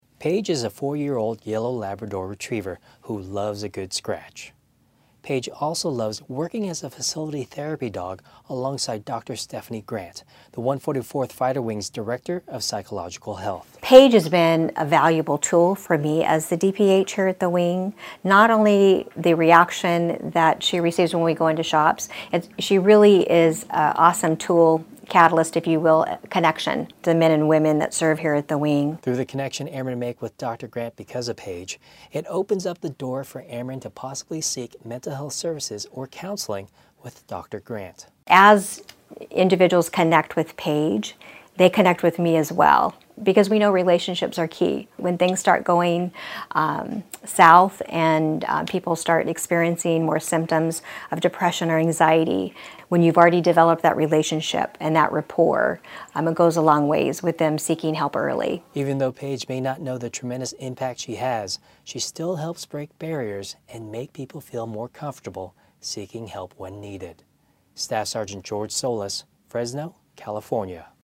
TITLE: A HELPING PAW-RADIO STORY